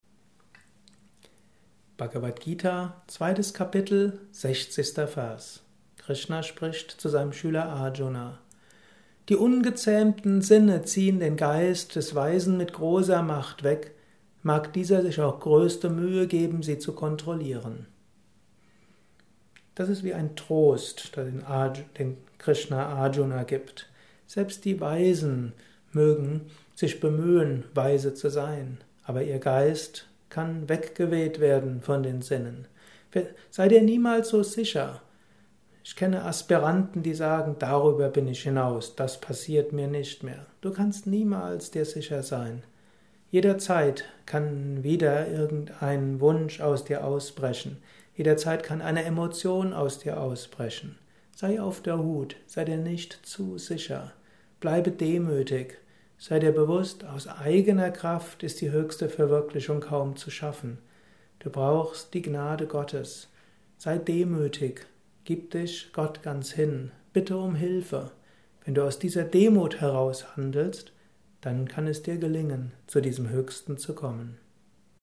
Kurzvortrag über die Bhagavad Gita